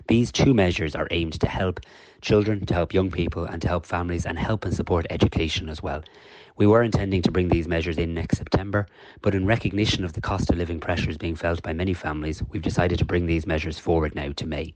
Taoiseach Simon Harris says the move has been brought in earlier than planned: